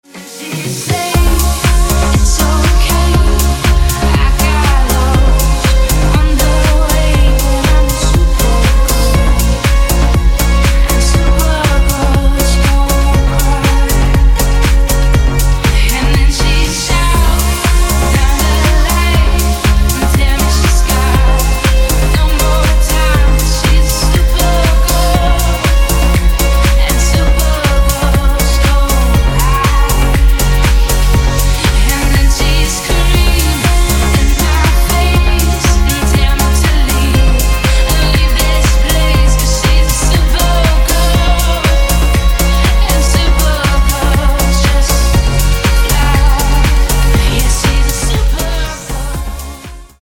• Качество: 160, Stereo
женский вокал
deep house